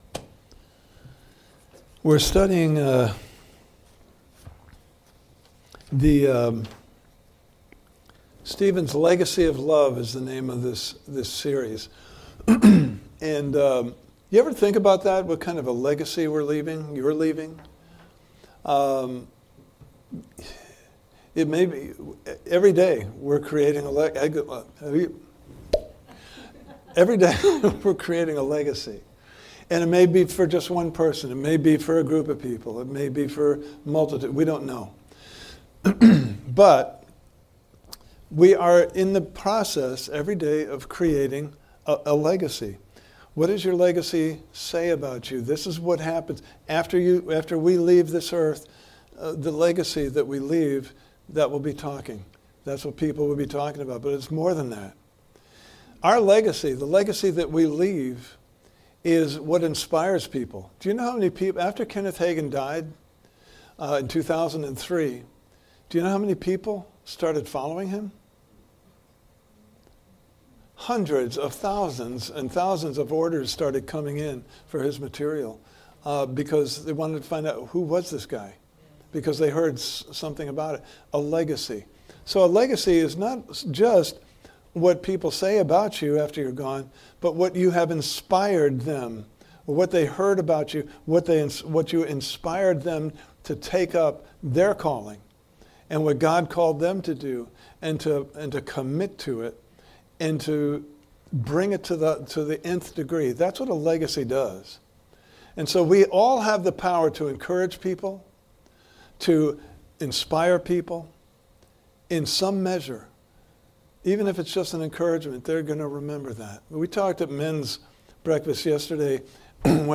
Series: Stephen’s Legacy of Love Service Type: Sunday Morning Service « Part 1